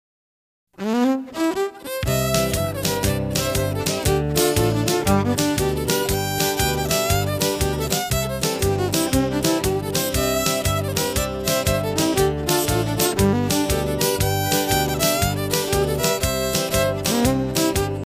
Fiddle Music